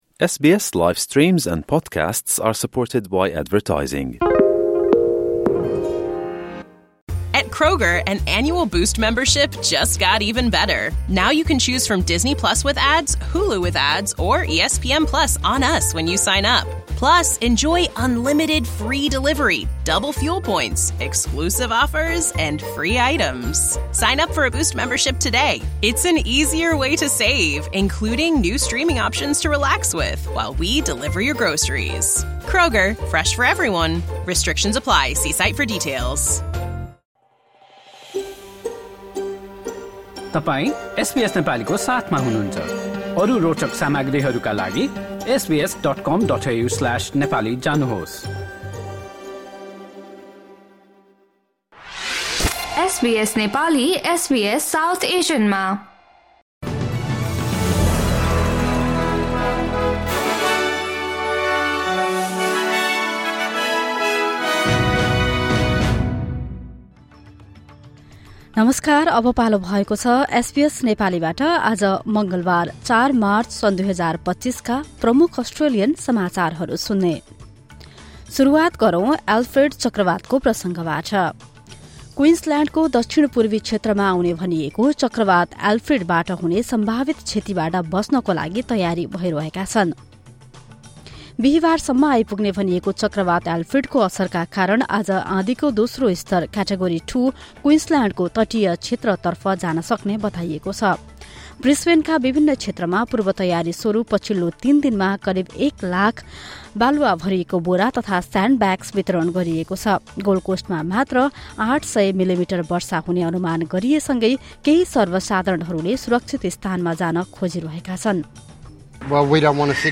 एसबीएस नेपाली प्रमुख अस्ट्रेलियन समाचार: मङ्गलवार, ४ मार्च २०२५